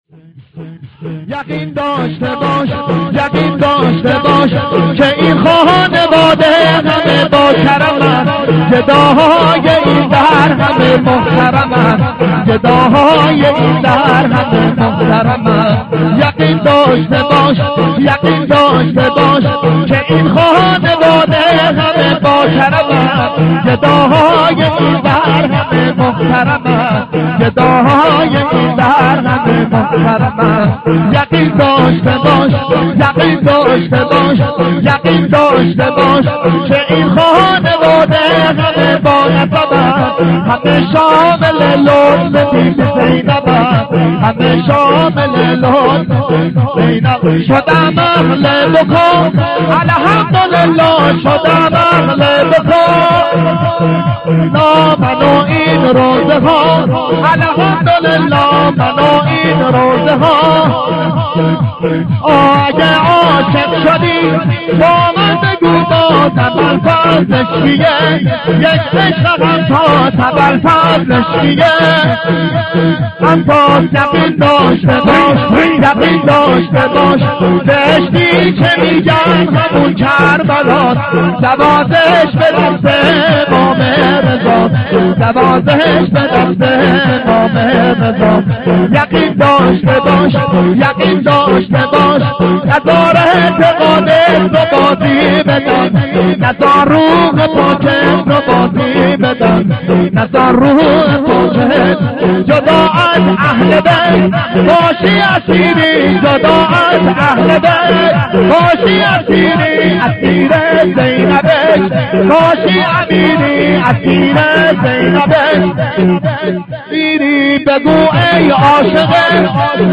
شب شهادت امام صادق(ع) ایام صادقیه(شب دوم) 94/05/19